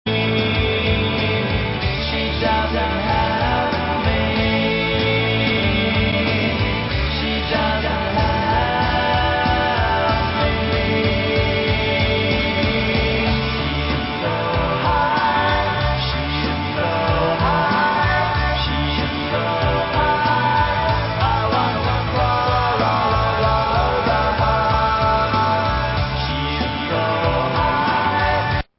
Indie Rock / Baggie / Brit Pop Classic Lp Reissue